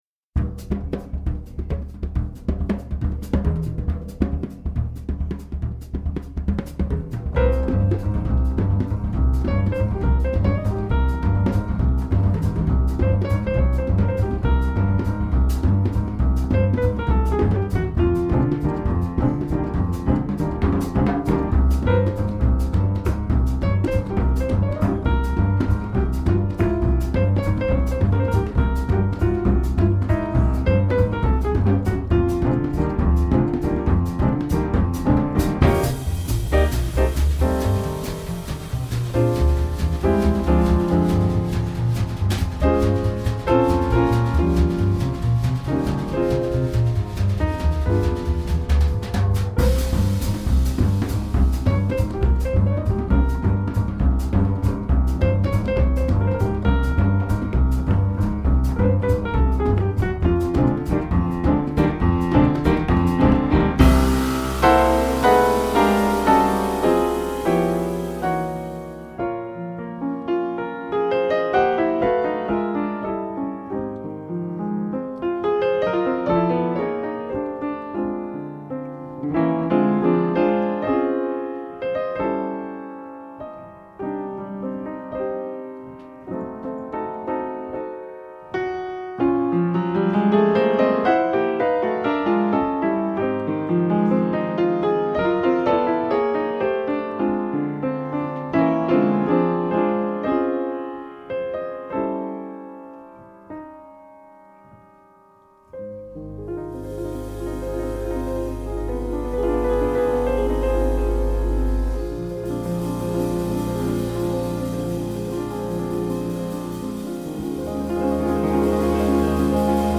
Jazz, Swing Bands
swinging jazz piano trio working in the UK today.